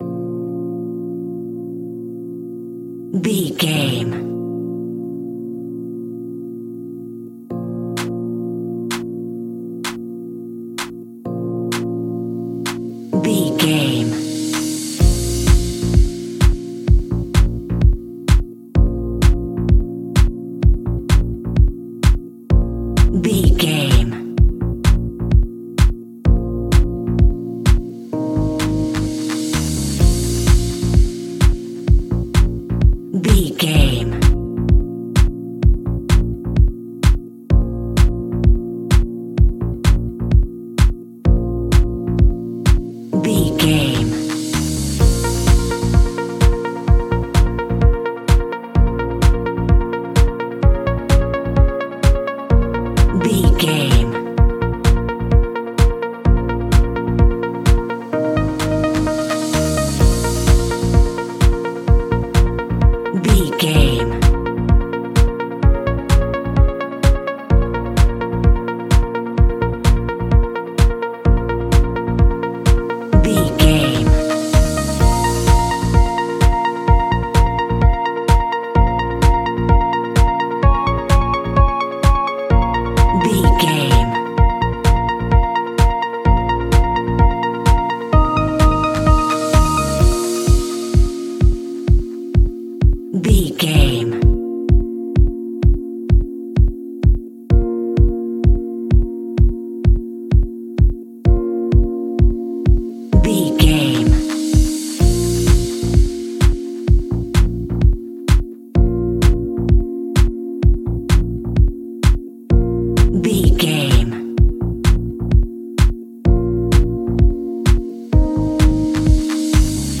Aeolian/Minor
B♭
groovy
uplifting
energetic
drum machine
synthesiser
bass guitar
funky house
upbeat